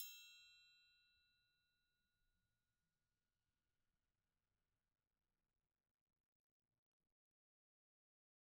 Triangle3-Hit_v1_rr1_Sum.wav